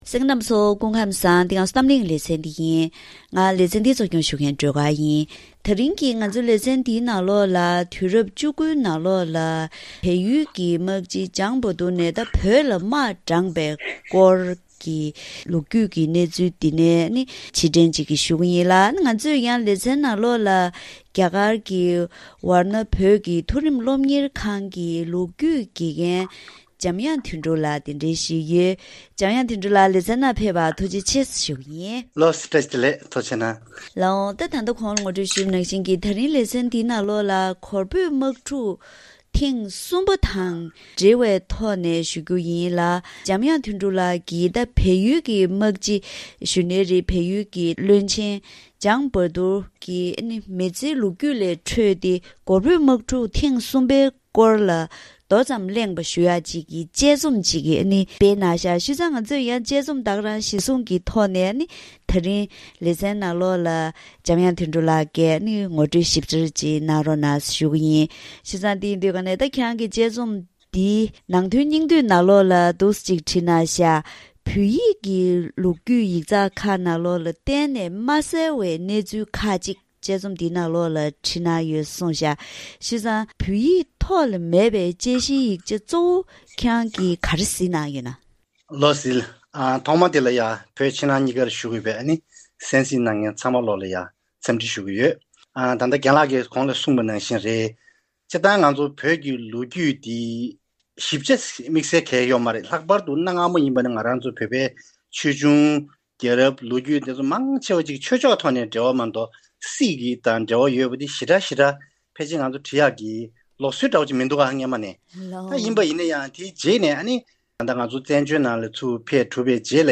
ད་རིང་གི་གཏམ་གླེང་ལེ་ཚན་འདིའི་ནང་བལ་བོད་ཀྱི་འབྲེལ་བ་འདི་བོད་བཙན་པོའི་རྒྱལ་ཁབ་ནས་འགོ་བཙུགས་ཏེ་བོད་ཀྱི་ཆབ་སྲིད་གནས་སྟངས་ལ་འགྱུར་བ་ཕྱིན་ཡོད་རུང་དམངས་ཁྲོད་ཀྱི་འབྲེལ་བ་འདི་མུ་མཐུད་ཡོད་བཞིན་པ་ཞིག་དང་། དམིགས་བསལ་ལོ་རྒྱུས་ཐོག་གོར་བོད་དམག་འཁྲུག་རིམ་པ་བྱུང་པའི་ཁོངས་ནས་དུས་རབས་བཅུ་དགུའི་ནང་བལ་ཡུལ་གྱི་དམག་སྤྱི་འཇང་སྦ་དྷུར་གྱིས་བོད་ལ་དམག་དྲངས་པའི་ལོ་རྒྱུས་ཀྱི་གནས་ཚུལ་ལ་ཕྱིར་དྲན་ཤེས་རྟོགས་ཞུས་པའི་ཐོག་ནས་འབྲེལ་ཡོད་དང་ལྷན་དུ་བཀའ་མོལ་ཞུས་པ་ཞིག་གསན་རོགས་གནང་།